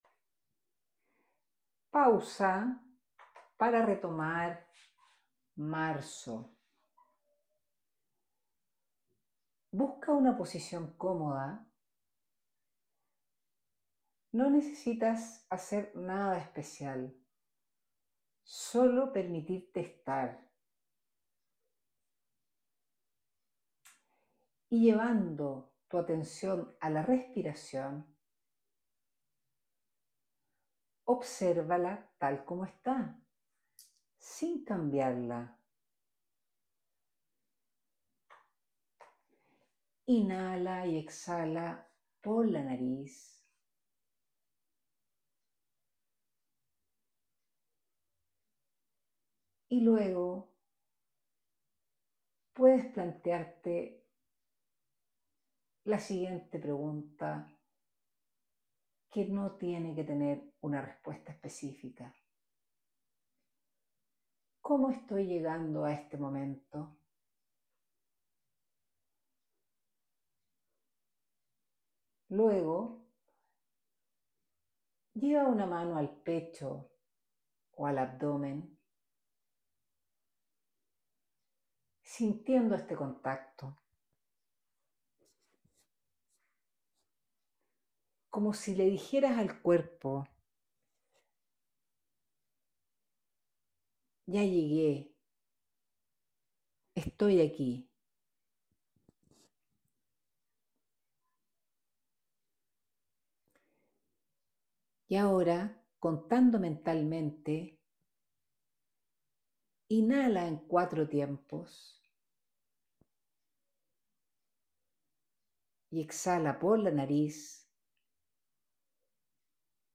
En medio de esta vorágine encuentra un espacio en el cual conectar contigo, en este audio guiado, te invito a detenerte 3-4 minutos.